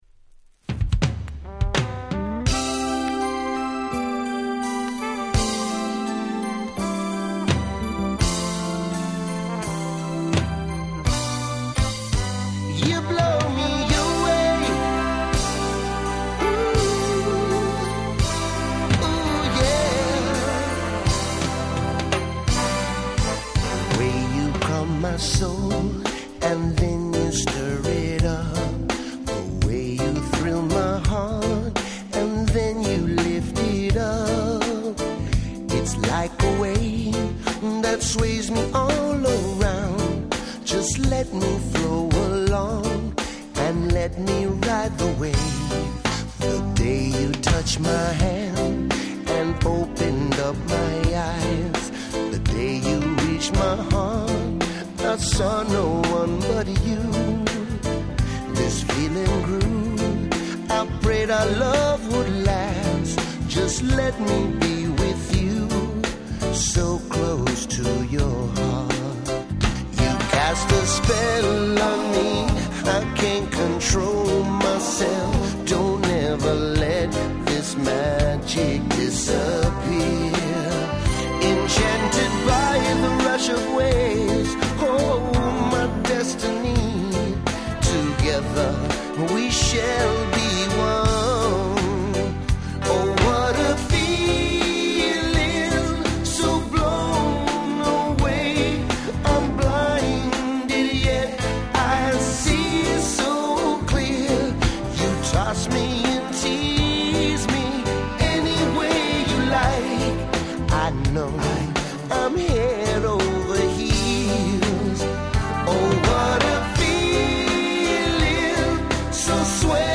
Nice Cover Reggae !!
ラバーズレゲエ